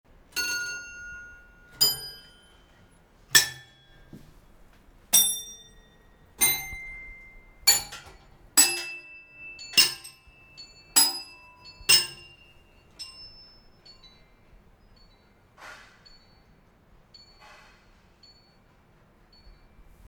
今回の訪問では，実際に筆者らも音と戯れる体験をし，さらにミュージアムの音を収録させていただきました。
♪「レールチャイム」